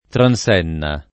tranS$nna o tranS%nna] s. f. — voce lat. entrata nell’uso it. nel corso del ’900 con sign. diverso dal classico, e diffusa largam. dopo la metà del secolo con sign. ancóra diverso («barriera smontabile»): di qui un legame sempre più debole con la base lat. e una tendenza sempre crescente a trascurare la regola dell’-e- aperta nelle voci di formaz. dòtta, accettando in cambio l’analogia dell’-e- chiusa di altre voci trisillabe in -enna (antenna, cotenna, tentenna) — discorso simile per le forme rizotoniche del der. transennare